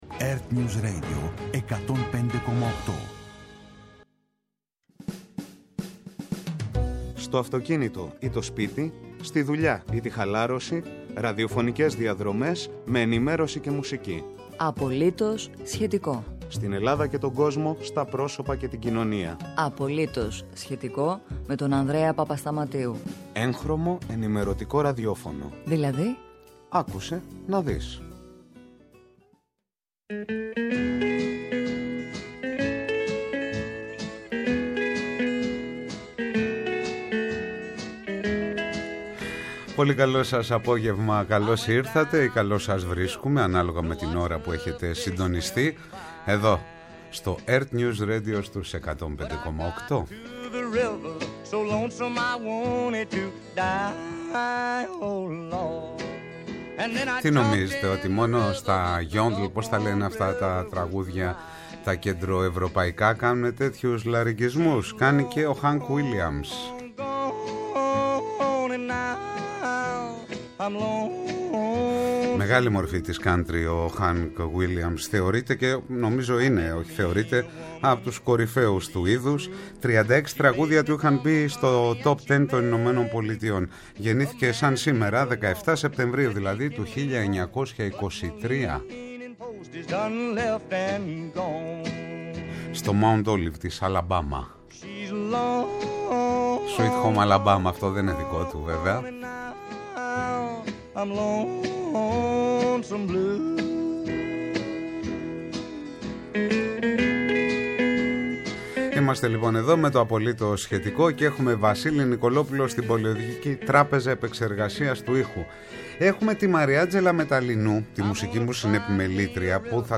Αυτοκίνητο ή σπίτι, δουλειά ή χαλάρωση, ραδιοφωνικές διαδρομές σε Ελλάδα και Κόσμο, σε Πρόσωπα και Κοινωνία.